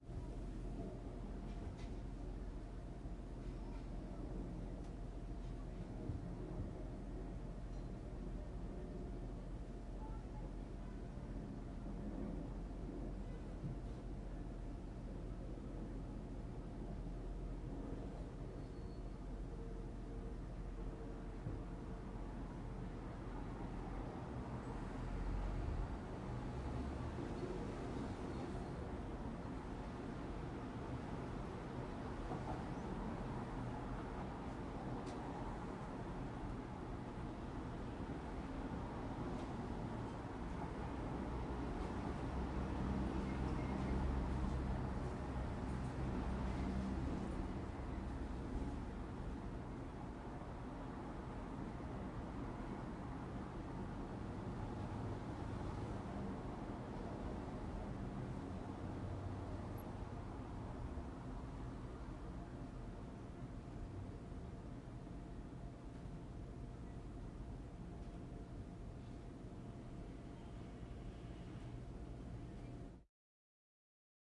花园环境氛围
描述：使用Zoom HN4进行录制。
标签： 房间 郊外 环境 交通 住宅 花园
声道立体声